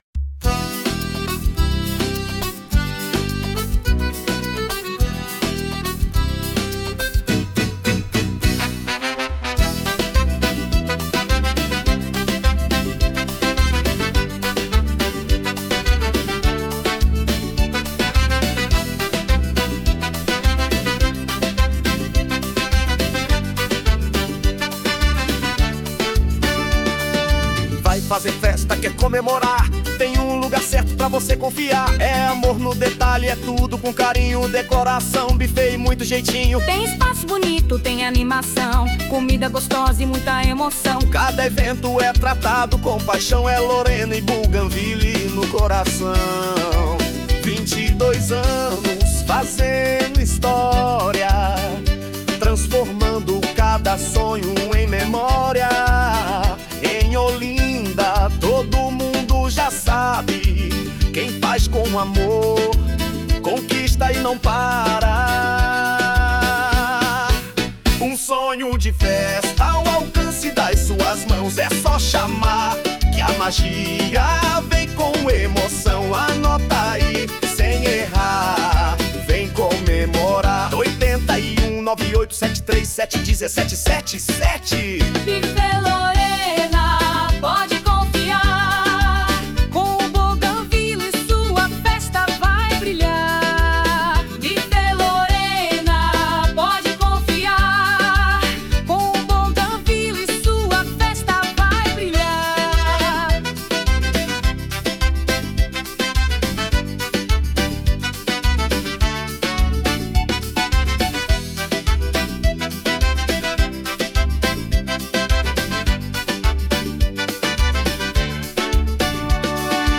JINGLE COMERCIAL
Forró Romântico Família Eventos